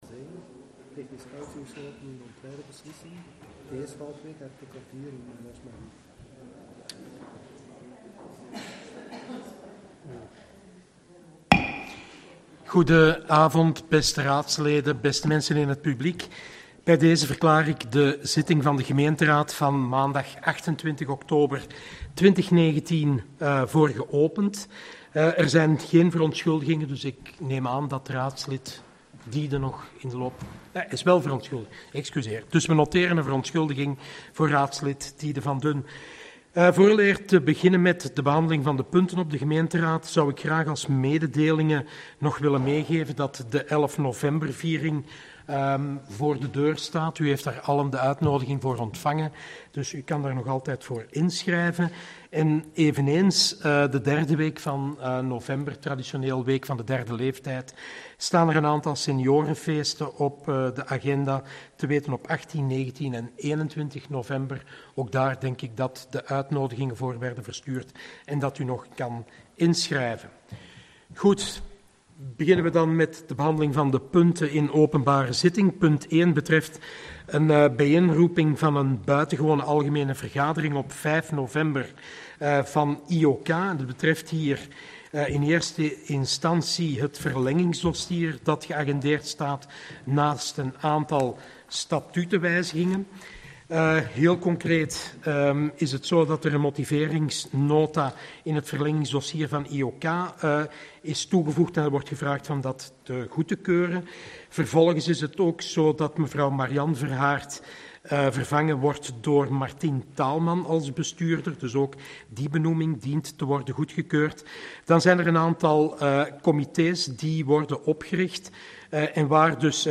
Gemeenteraad 28 oktober 2019 - Zittingsverslag